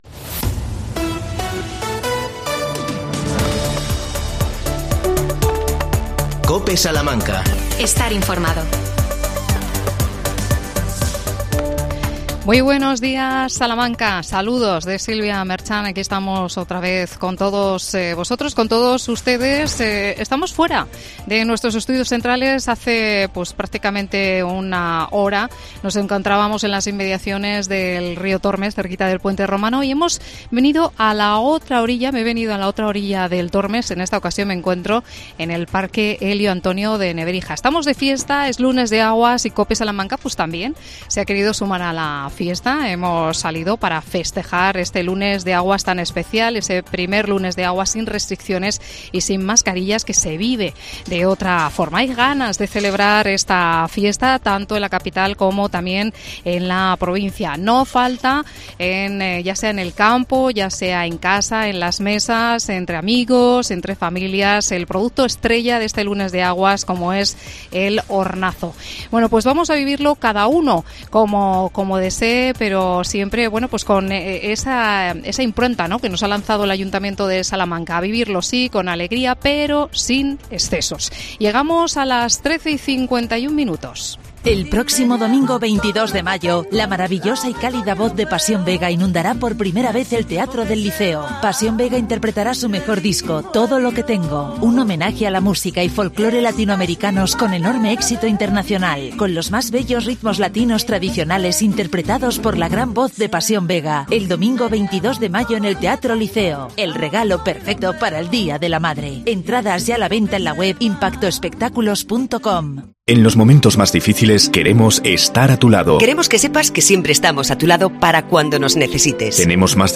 AUDIO: Hablamos con el concejal de Turismo desde el parque Elio Antonio de Nebrija